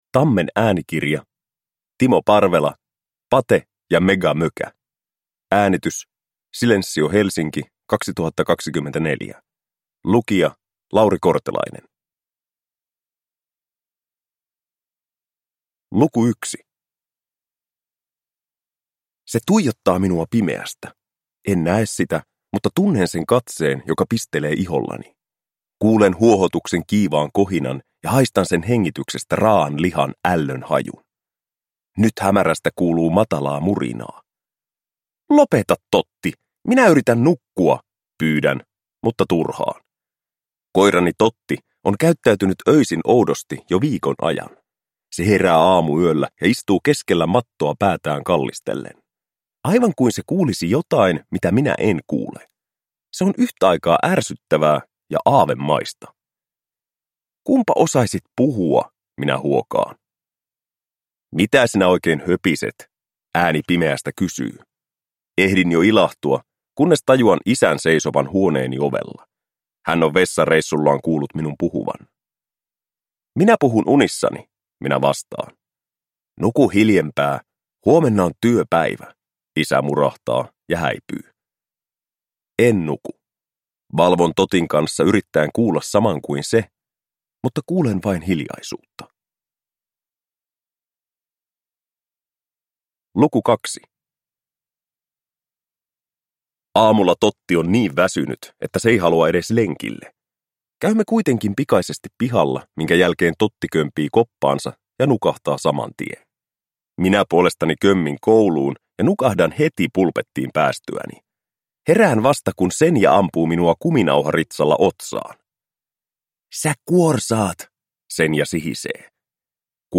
Pate ja Megamökä – Ljudbok